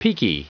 Prononciation du mot peaky en anglais (fichier audio)
Prononciation du mot : peaky